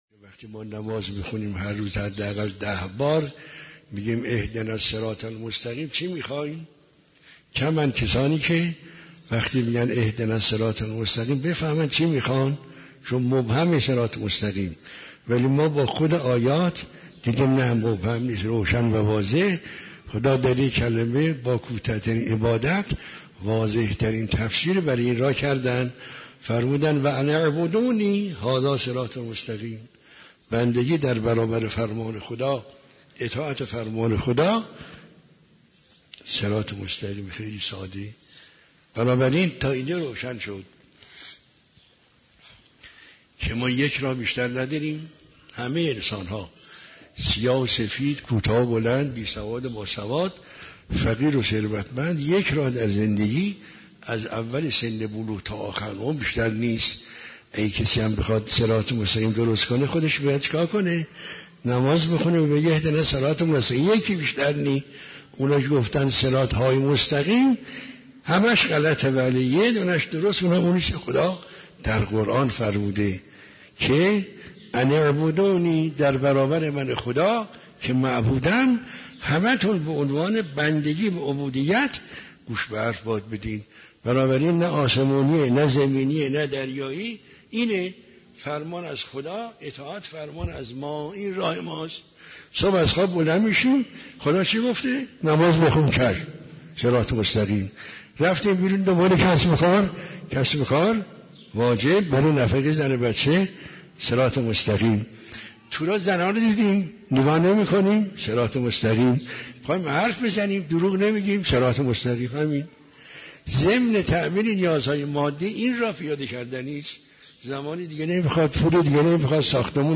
دروس اخلاق